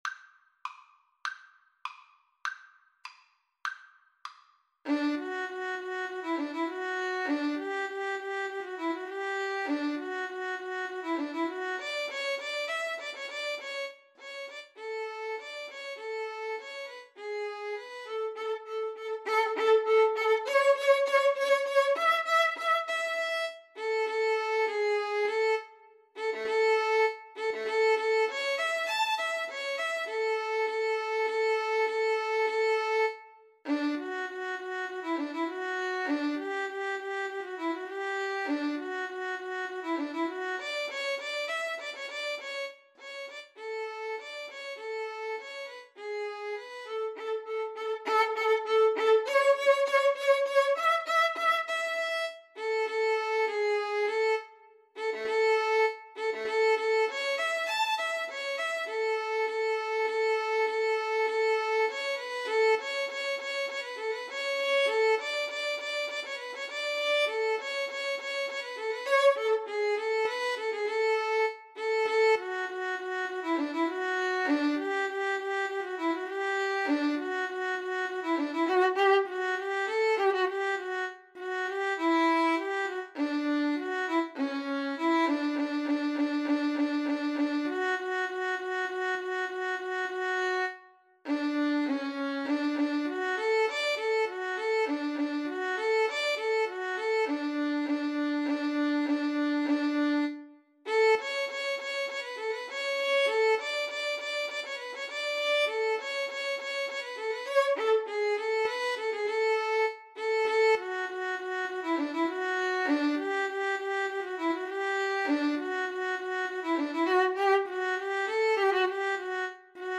Play (or use space bar on your keyboard) Pause Music Playalong - Player 1 Accompaniment reset tempo print settings full screen
~ = 100 Allegretto
D major (Sounding Pitch) (View more D major Music for Violin Duet )
Classical (View more Classical Violin Duet Music)